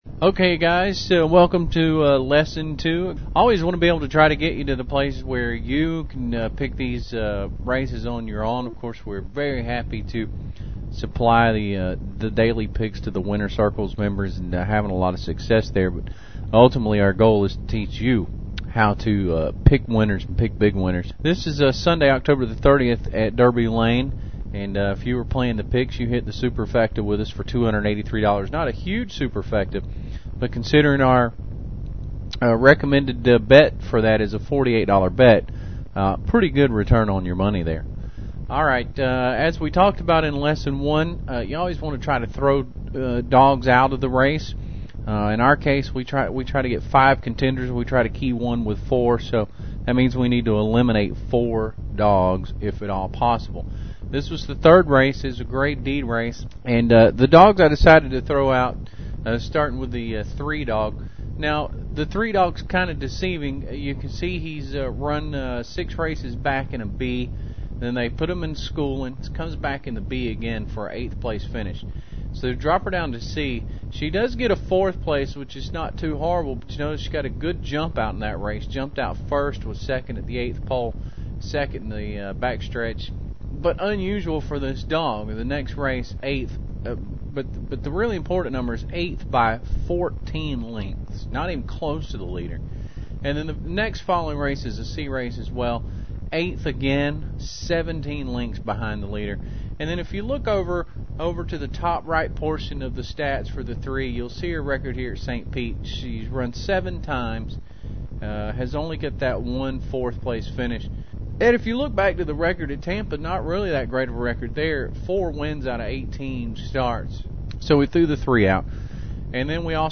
Lesson 2